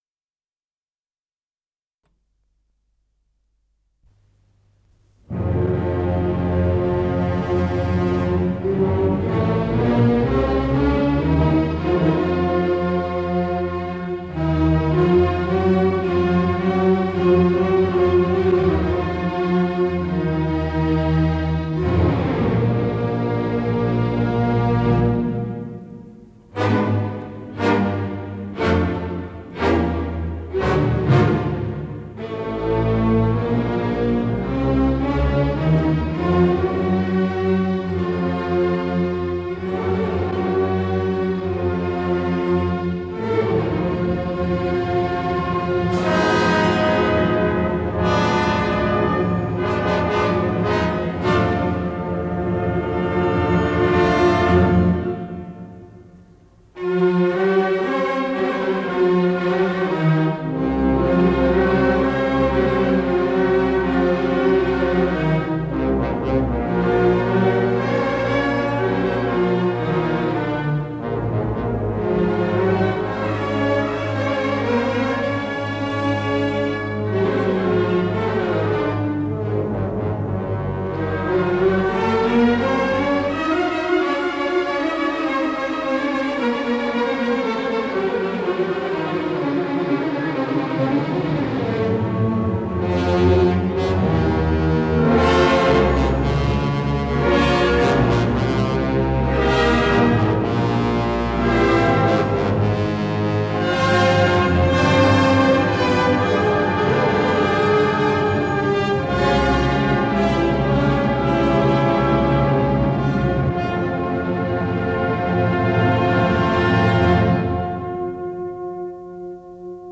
Ре минор, части идут без перерыва